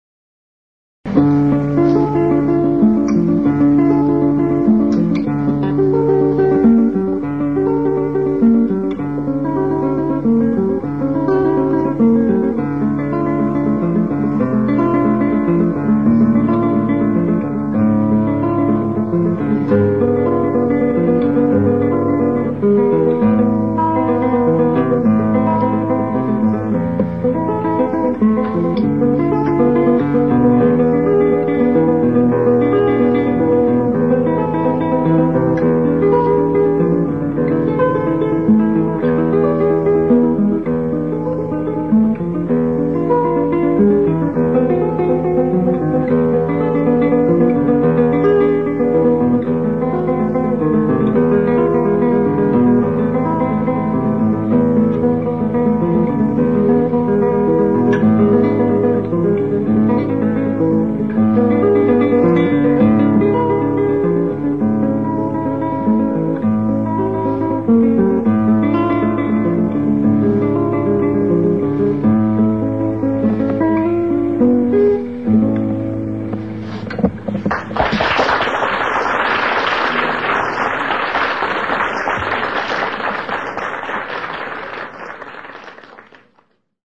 Classical Guitar
Classical Guitar Kresge Little Theater MIT Cambridge, Massachusetts USA March 22